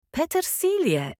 parsley-in-german.mp3